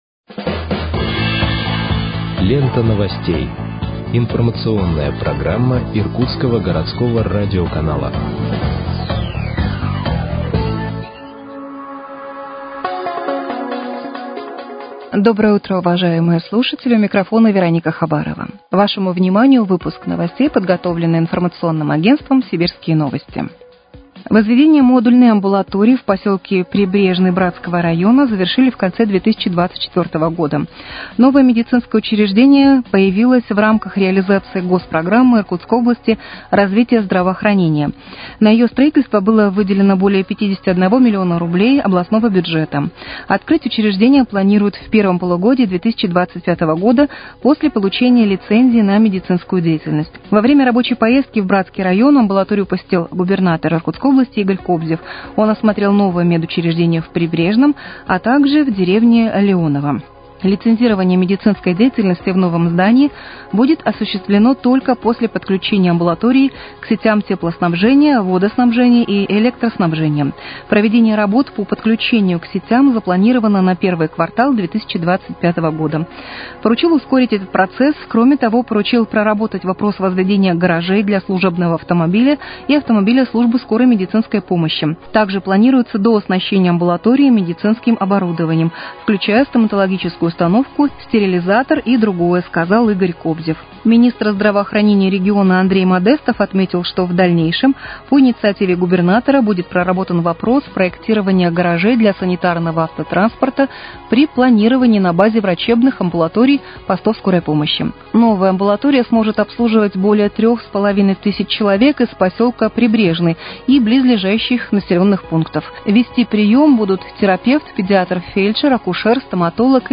Выпуск новостей в подкастах газеты «Иркутск» от 14.01.2025 № 1